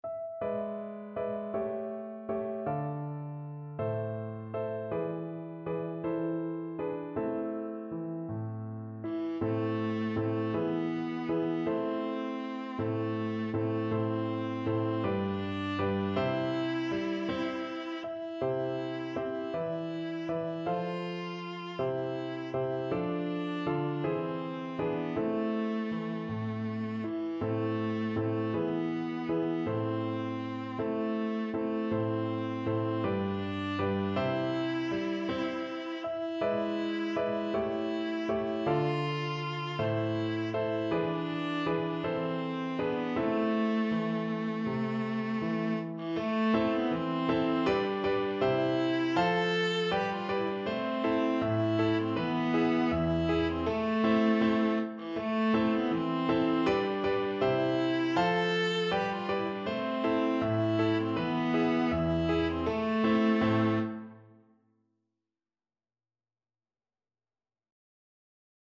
Viola
A minor (Sounding Pitch) (View more A minor Music for Viola )
Andante
6/8 (View more 6/8 Music)
Traditional (View more Traditional Viola Music)